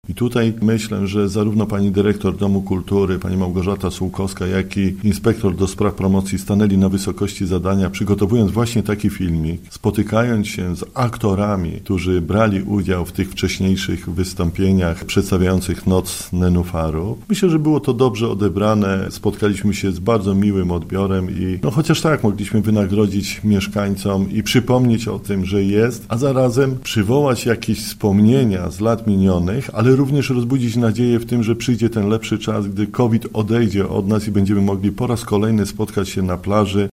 Wójt, dodaje, że w filmie pojawiają się mieszkańcy, którzy dotychczas występowali w spektaklu na wodzie: